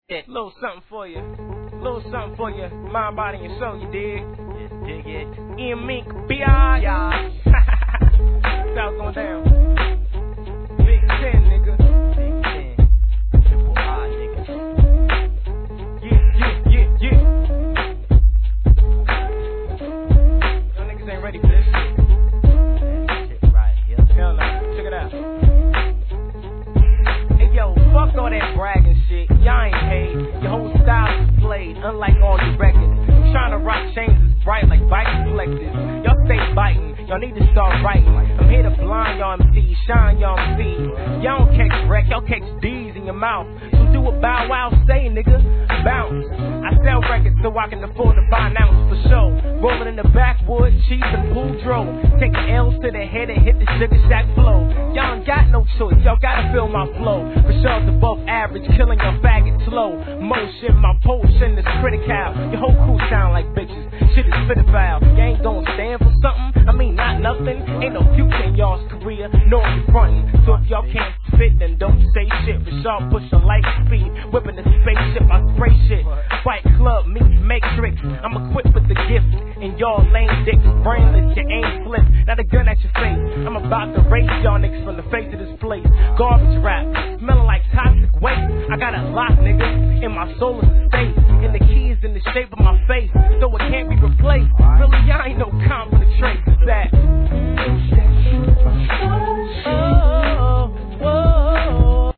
HIP HOP/R&B
落ち着いたムーディーなネタ使いにフックのコーラスがR&B色MAXで、でら逸品!!